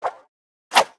swing_1.wav